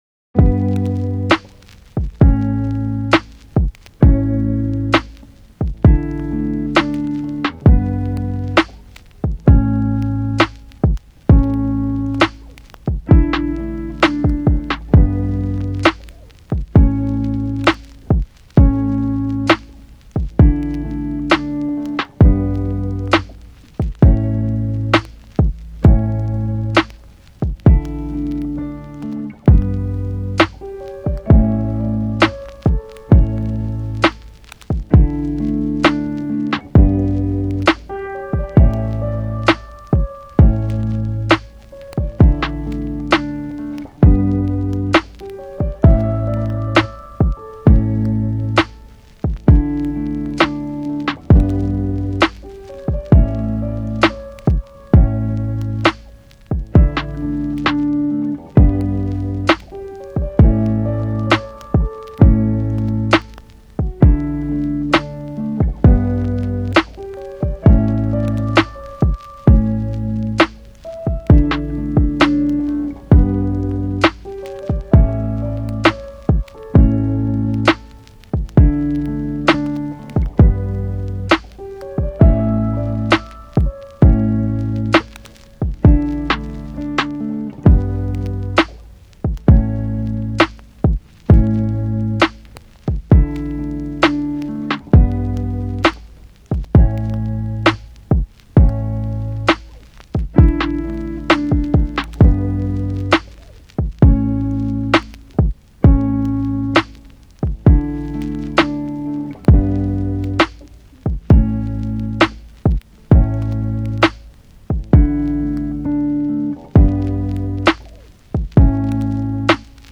チル・穏やか フリーBGM